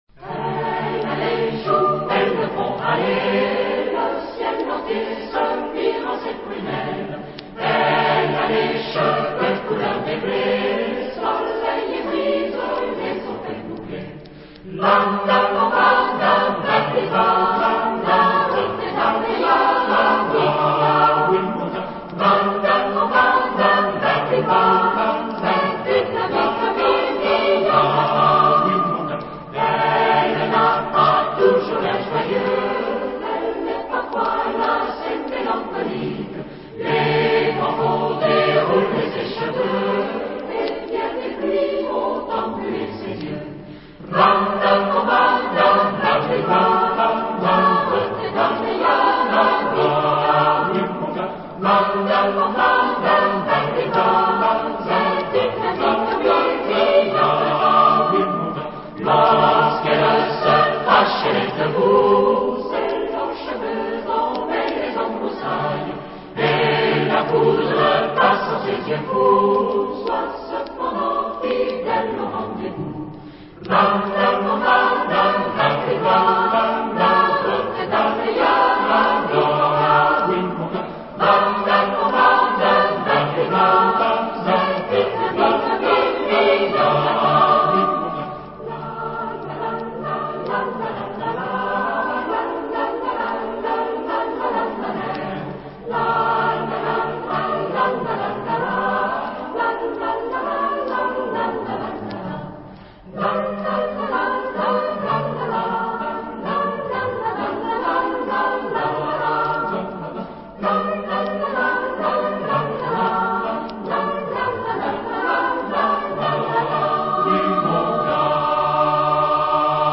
Genre-Style-Forme : Populaire ; Chanson ; Profane
Caractère de la pièce : mélancolique
Type de choeur : SATB  (4 voix mixtes )
Tonalité : ré majeur